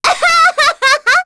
Rehartna-Vox_Happy5.wav